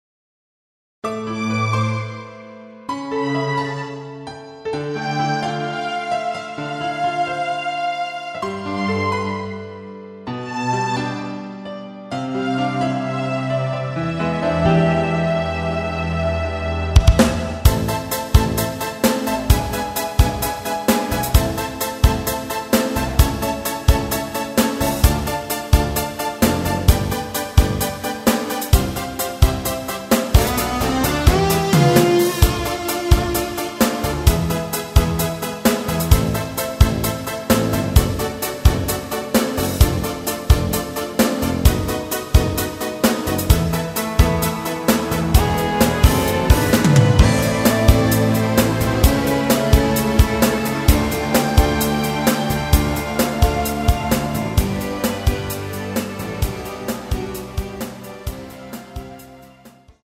Am
앞부분30초, 뒷부분30초씩 편집해서 올려 드리고 있습니다.
중간에 음이 끈어지고 다시 나오는 이유는